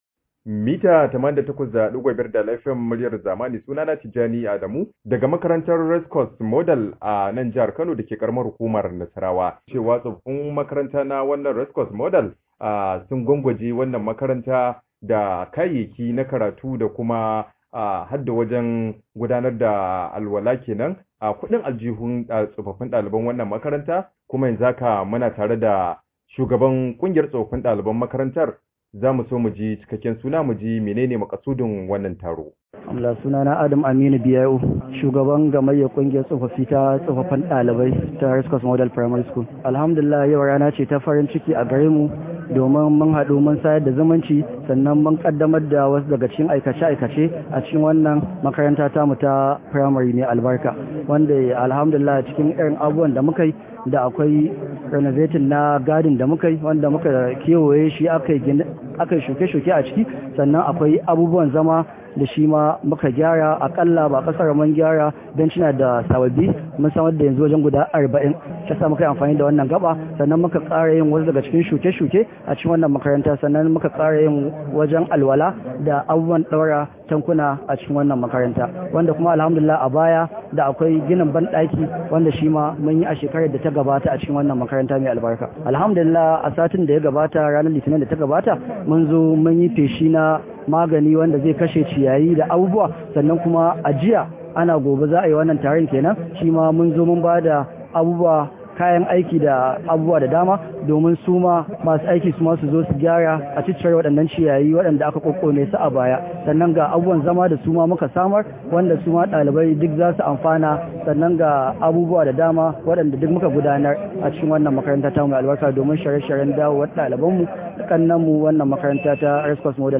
Rahoto: Gwamnatin Kano za ta dauki tsofaffin dalibai aiki